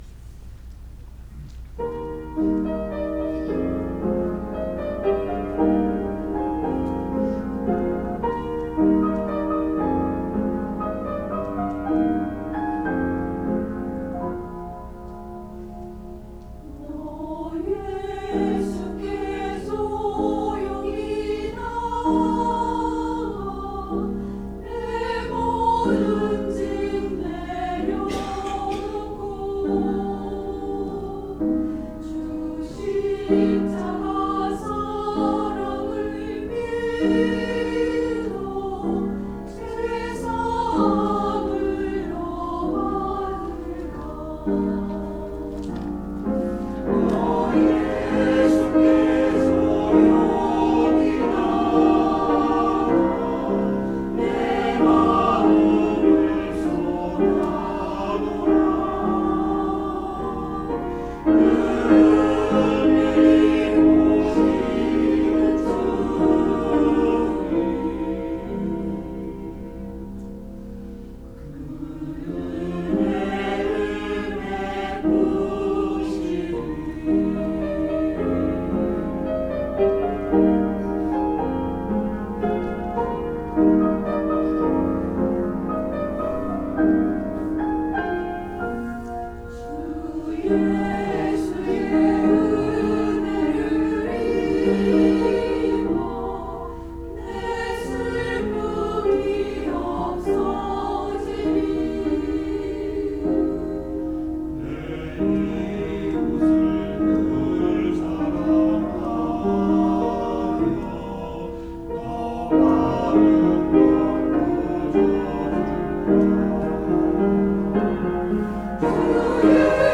찬양대
[주일 찬양] 너 예수께 조용히 나가